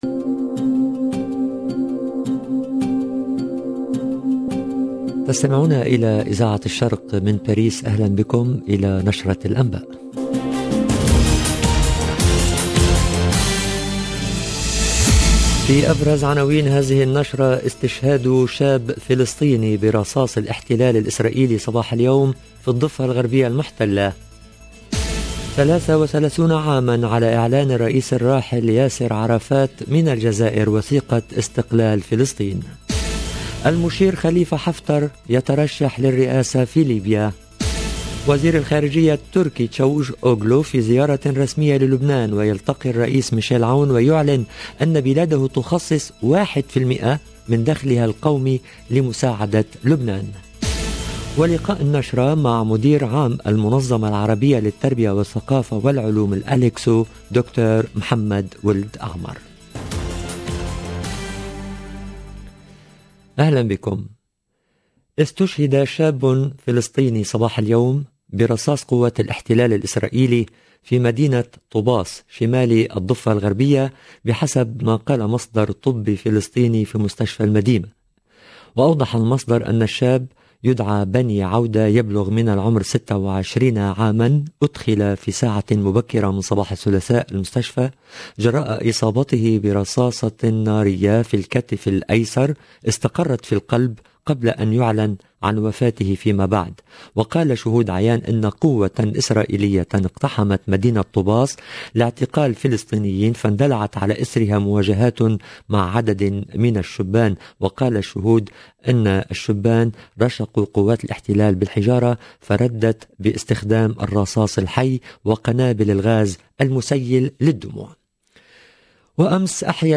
LE JOURNAL DE MIDI 30 EN LANGUE ARABE DU 16/11/21